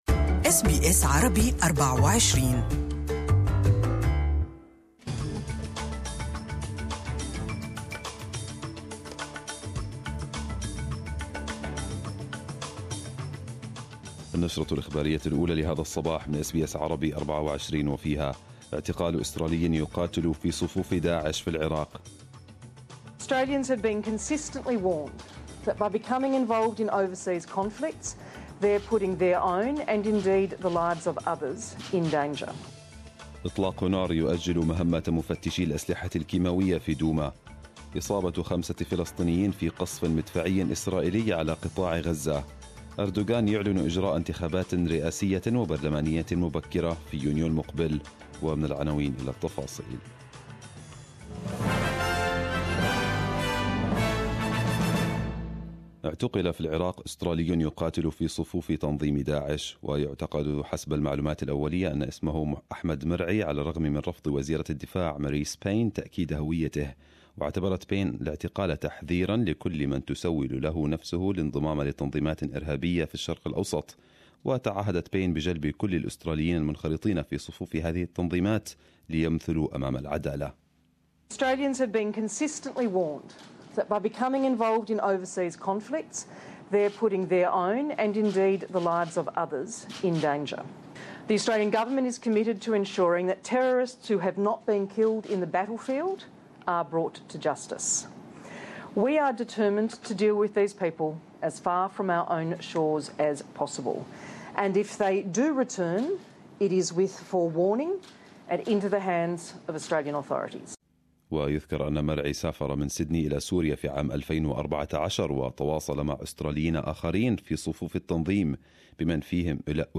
Arabic News Bulletin 19/04/2018